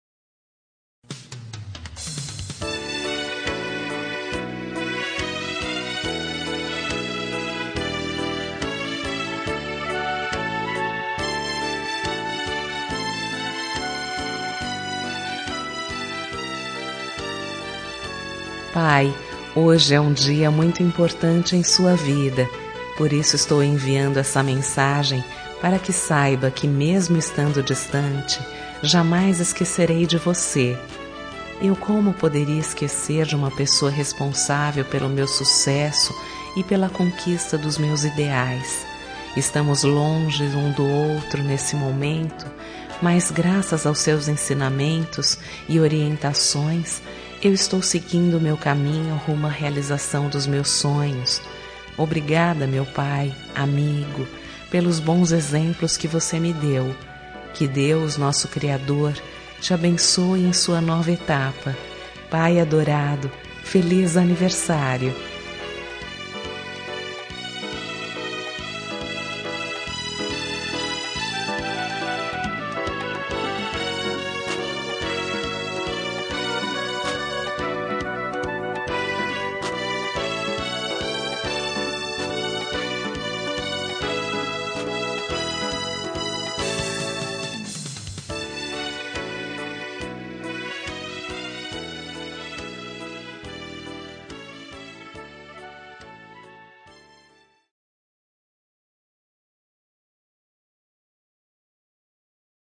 Telemensagem de Aniversário de Pai – Voz Feminina – Cód: 1483 Distante